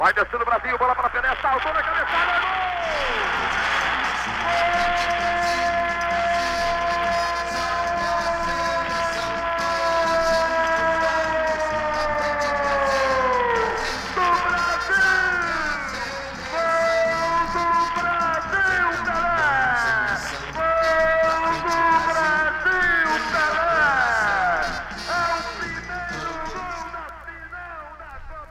Confira algumas narrações de gols importantes para a carreira do maior jogador de futebol de todos os tempos: